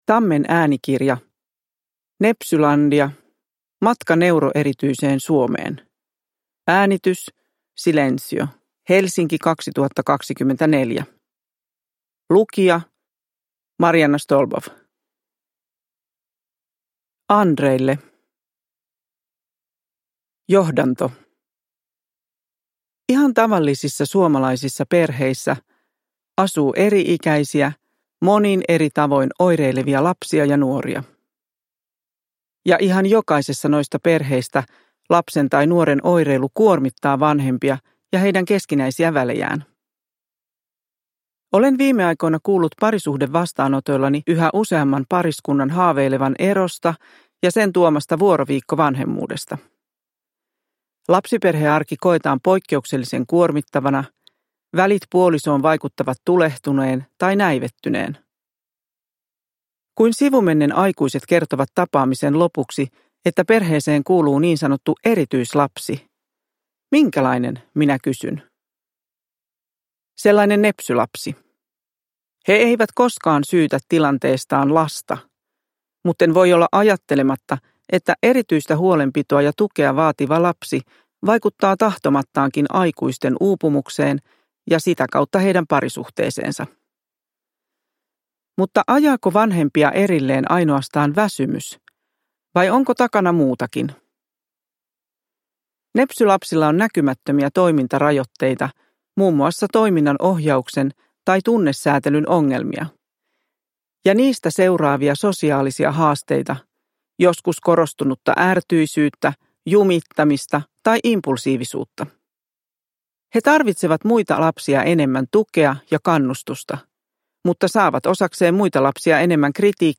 Nepsylandia – Ljudbok